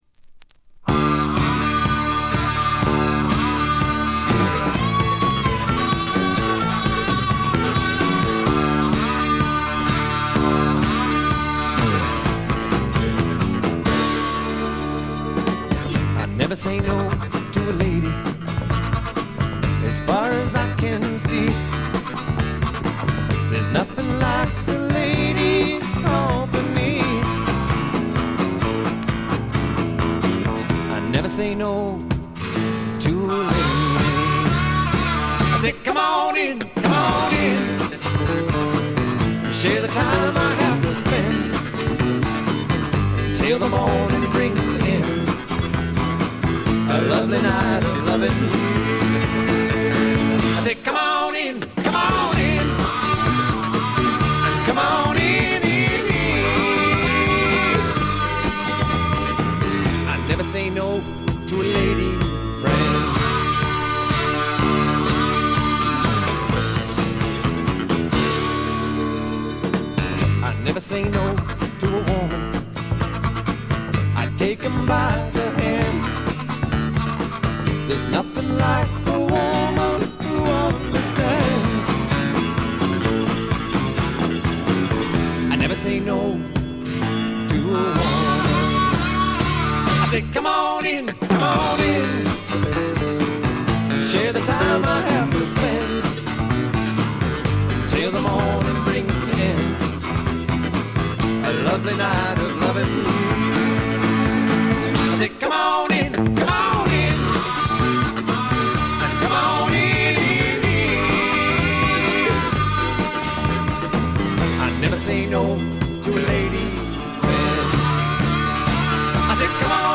部分試聴ですがほとんどノイズ感無し。
試聴曲は現品からの取り込み音源です。
Acoustic Guitar, Electric Guitar
Banjo, Harp
Fiddle
Steel Guitar